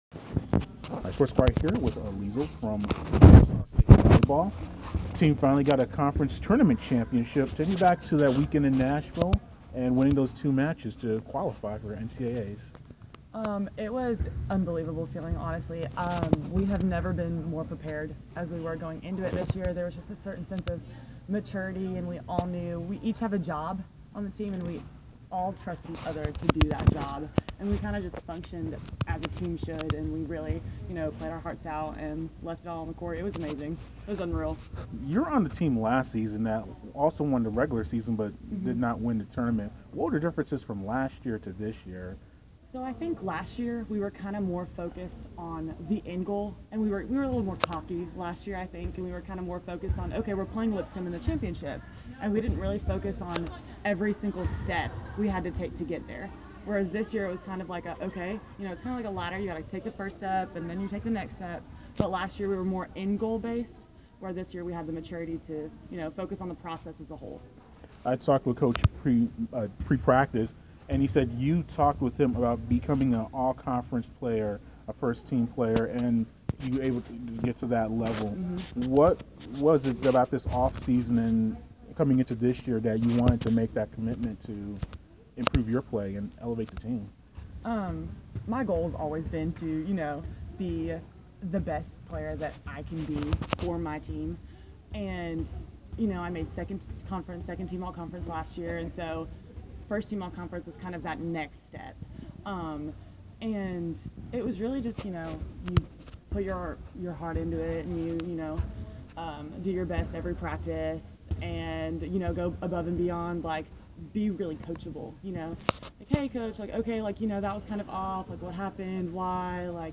Kennesaw State volleyball